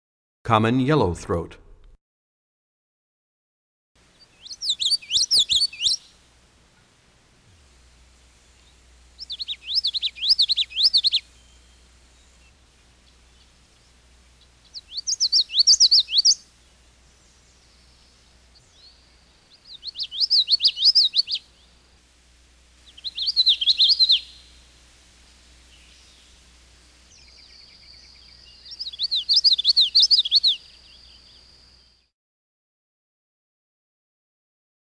28 Common Yellowthroat.mp3